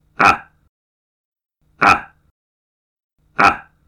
ah (short sound)